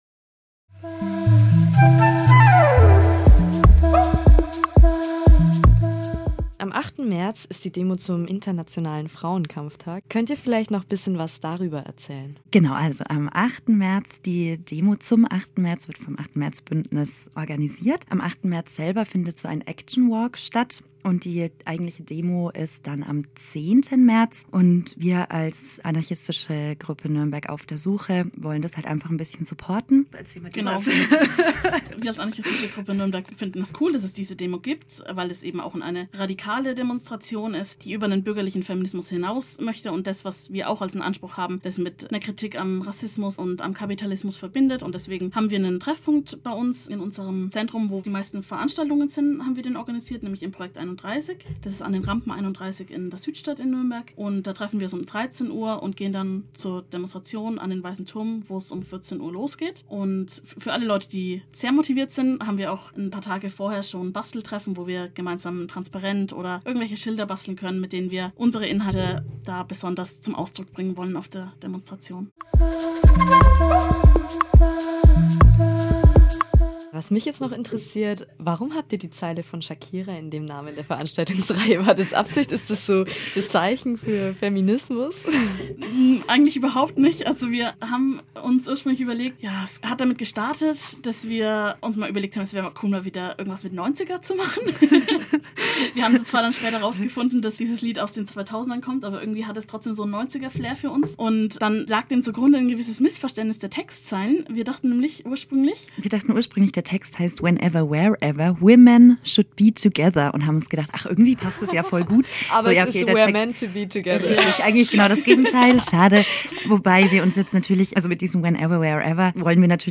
Archiv: Interview zur anarcha-feministischen Veranstaltungsreihe
Hier könnt ihr in ein Interview reinhören, das die Leute von Stoffwechsel bei Radio Z mit uns geführt haben:
InterviewFeminismusreihe3.mp3